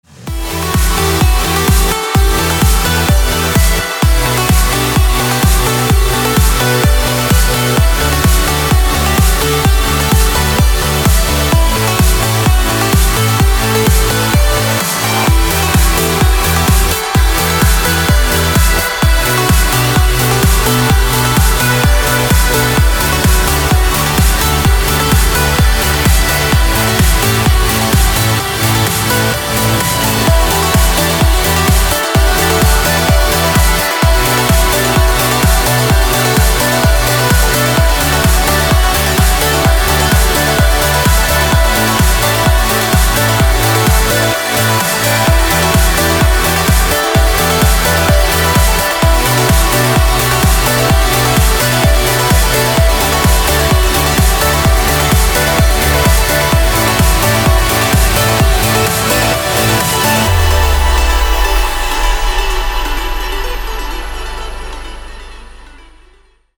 • Качество: 224, Stereo
громкие
женский голос
dance
Electronic
EDM
электронная музыка
progressive trance